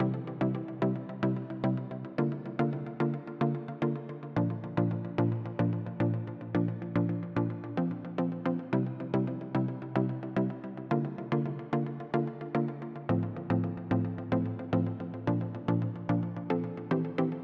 热带包4弹拨和弦
Tag: 110 bpm House Loops Synth Loops 2.94 MB wav Key : Unknown